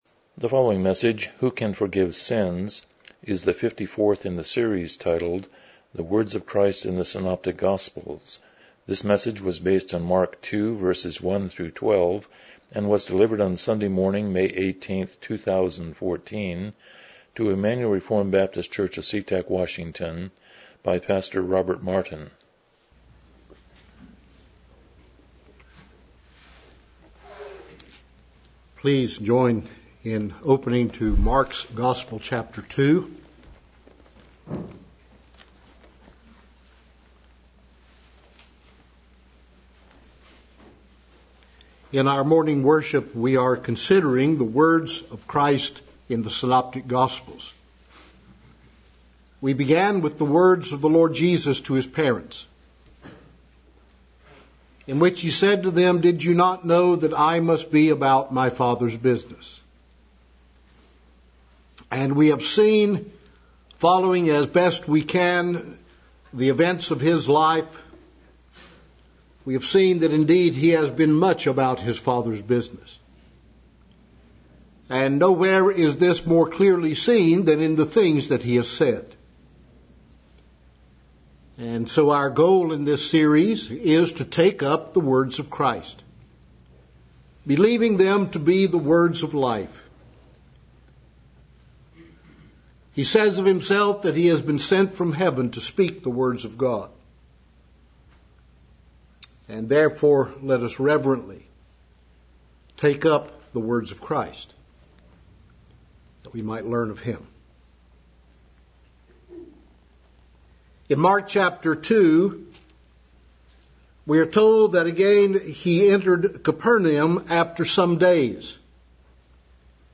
Mark 2:1-12 Service Type: Morning Worship « 37 God’s Eternal Love 20 How Does the New Testament Describe the Christian Life?